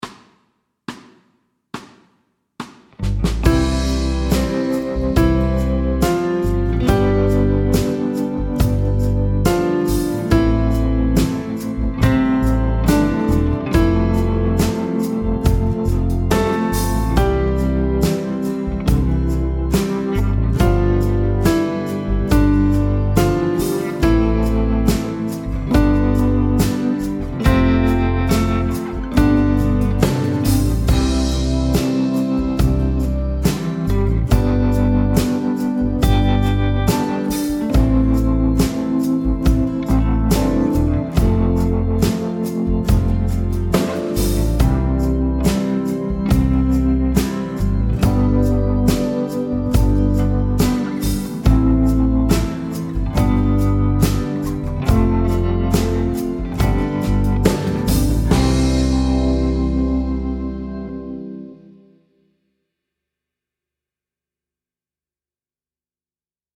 Medium C instr (demo)
Rytmeværdier: 1/1-, 1/2-, og 1/4 noder og pauser.